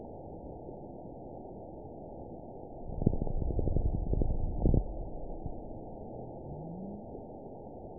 event 920417 date 03/23/24 time 23:18:37 GMT (1 month ago) score 9.44 location TSS-AB05 detected by nrw target species NRW annotations +NRW Spectrogram: Frequency (kHz) vs. Time (s) audio not available .wav